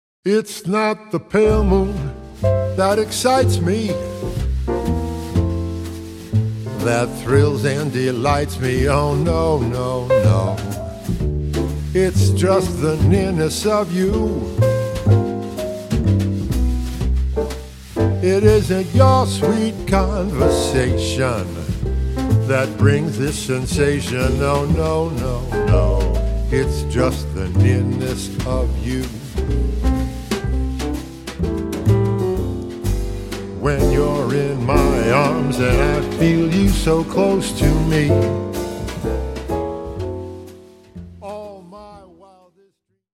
Jazz vocalist
smooth and supremely expressive baritone voice
vocals
piano
bass
drums